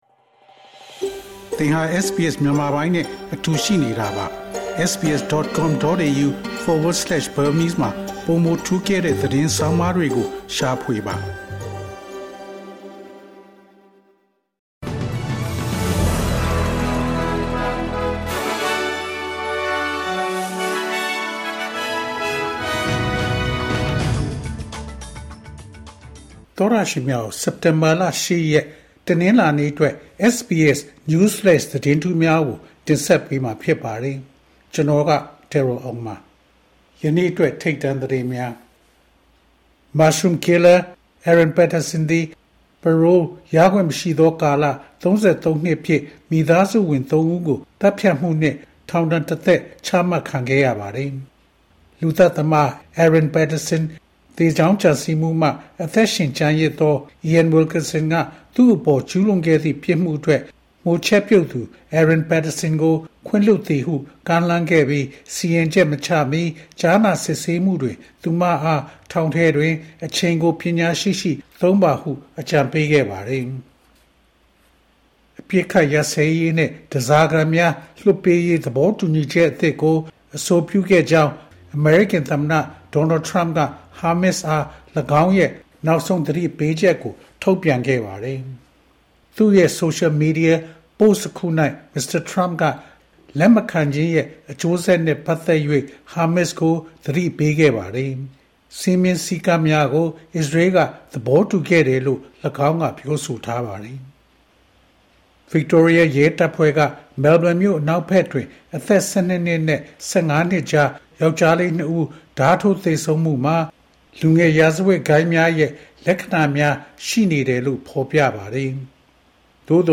SBS မြန်မာ ၂၀၂၅ ခုနှစ် စက်တင်ဘာလ ၈ ရက် နေ့အတွက် News Flash သတင်းများ။